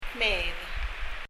kmeed　　　　[kmi:ð]
発音